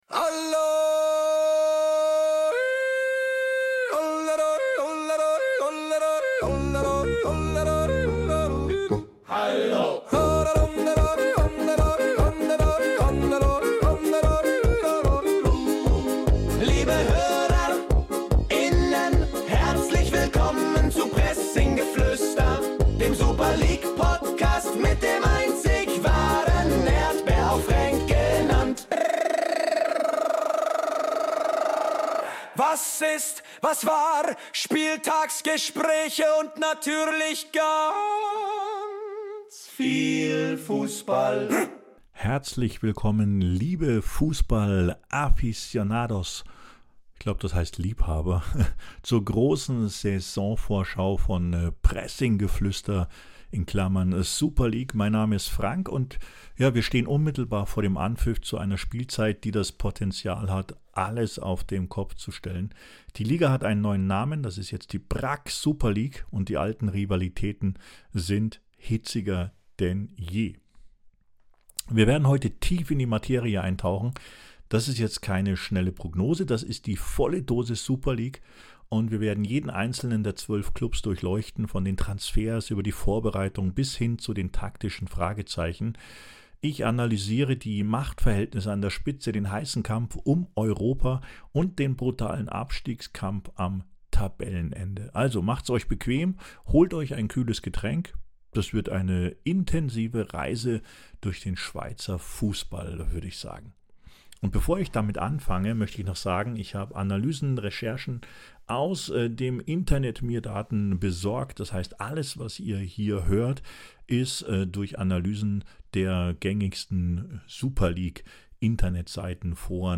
Vielleicht gefällt euch der Mix aus Jodeln und EDM.